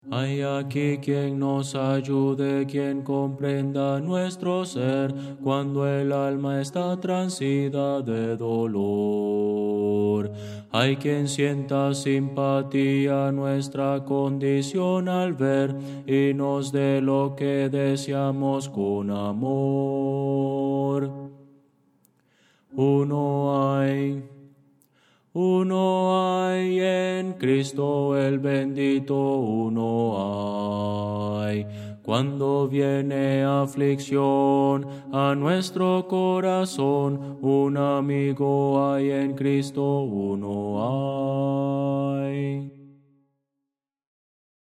Voces para coro
Audio: MIDI